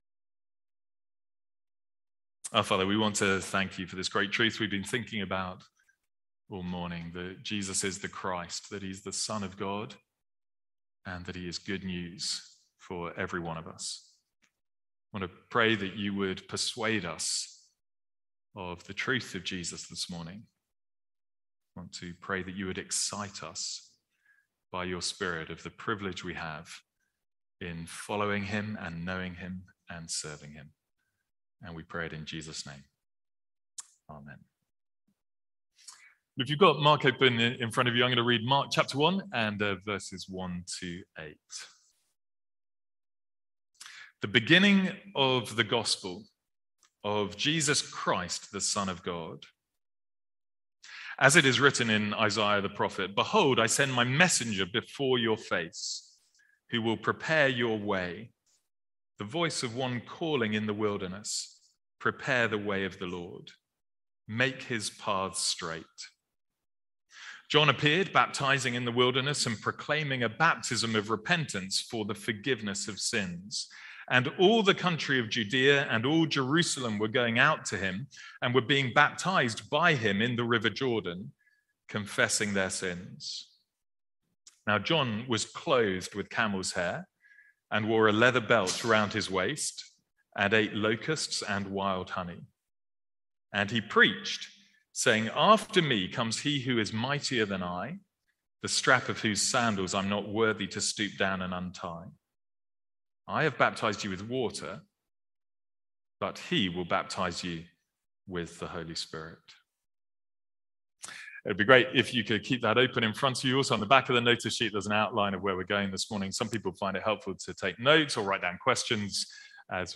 From our morning series in The Gospel of Mark.